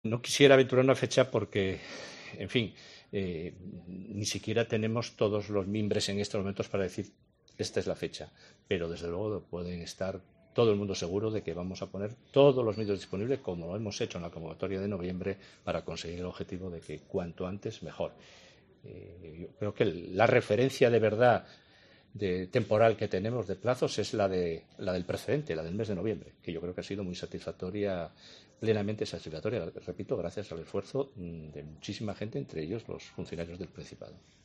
Juan Cofiño sobre las nuevas ayudas COVID